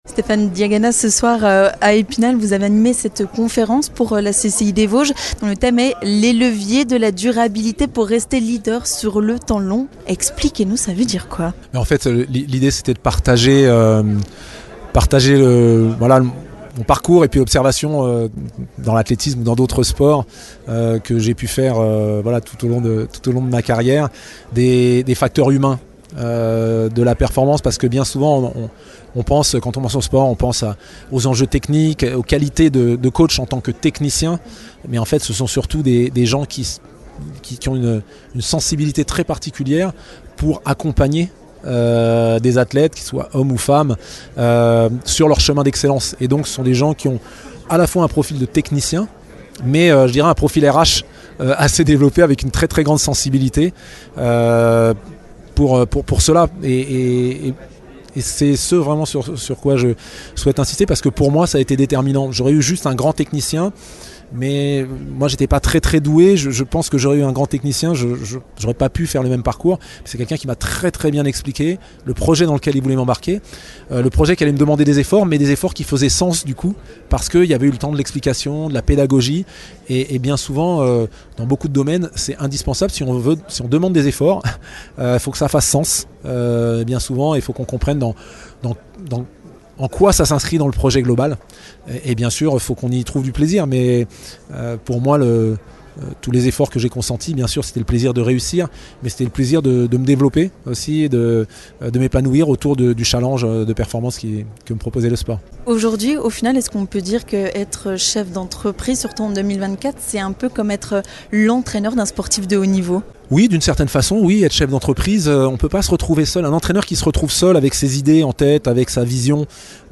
Mais, être chef d'entreprise en 2024, ce n'est pas un peu comme être l'entraîneur d'un sportif de haut niveau ? On en parle avec Stéphane Diagana !